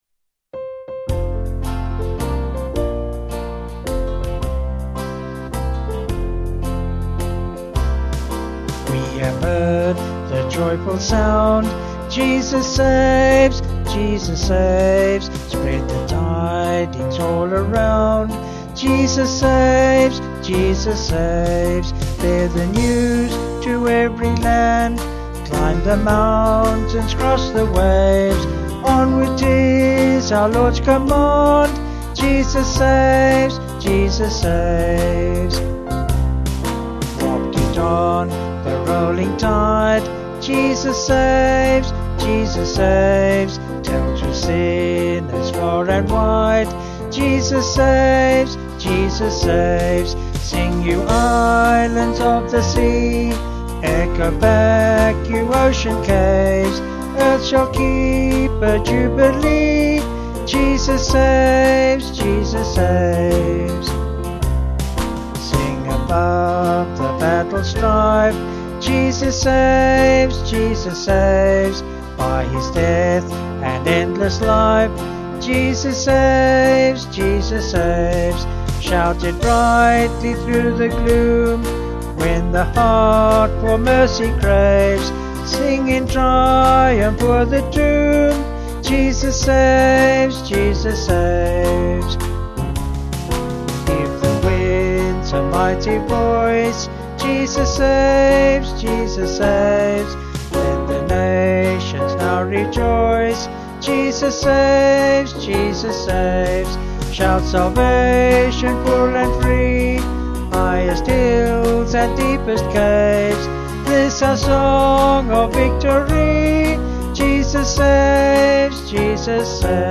Vocals and Band
267.4kb Sung Lyrics